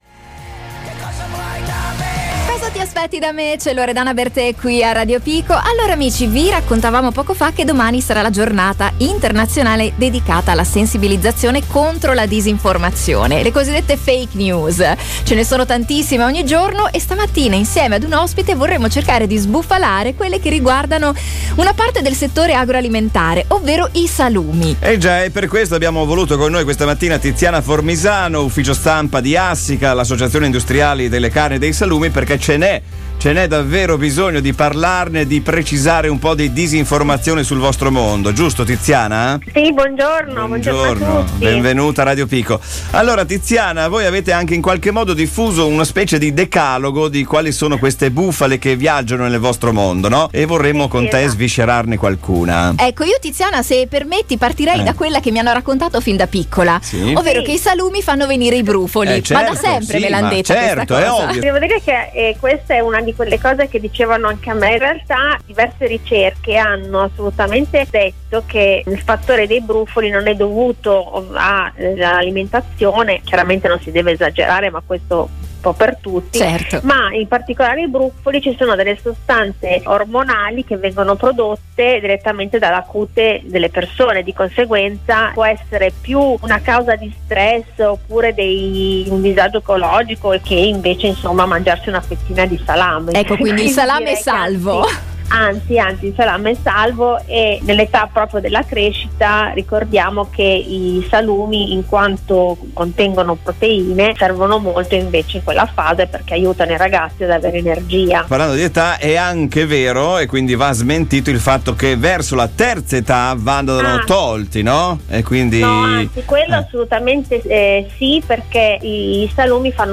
In occasione del 2 aprile, giornata internazionale dedicata alla sensibilizzazione contro la disinformazione, l’Istituto Valorizzazione Salumi Italiani stila un decalogo dei più celebri falsi miti sui salumi che per anni hanno veicolato nozioni errate, condizionando le scelte alimentari dei consumatori. Ne abbiamo parlato con